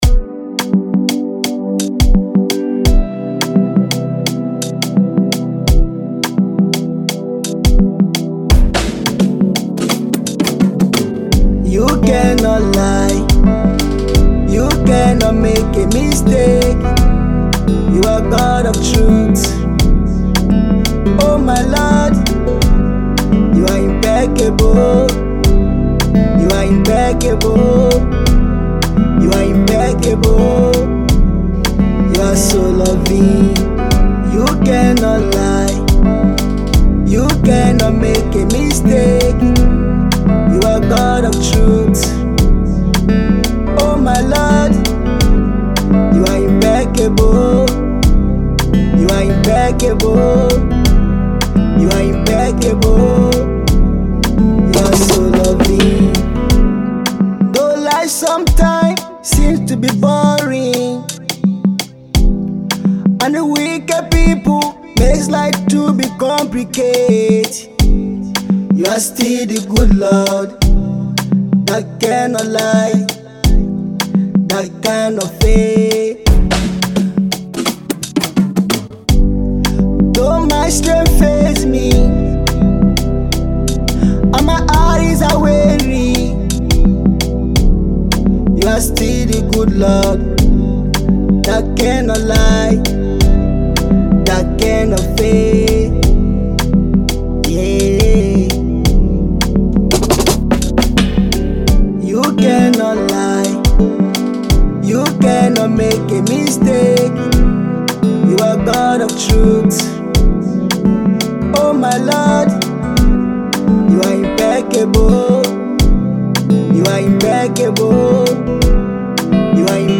Audio Bongo flava Latest